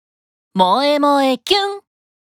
Chat Voice Files
Speaker Asmodeus